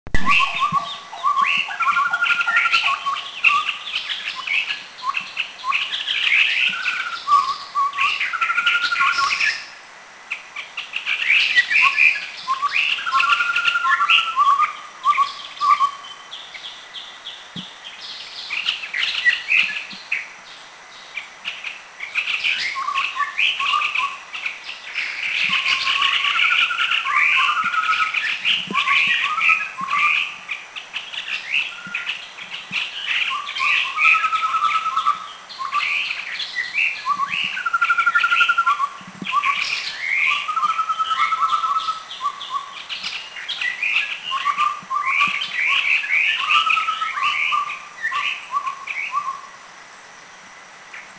Chestnut-rumped Babbler 3
Stachyris maculate
Chestnut-rumpedBabbler3.mp3